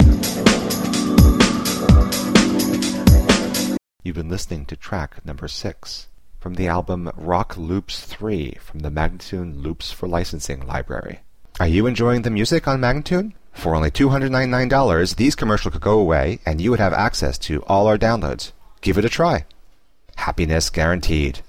Instrumental samples in many genres.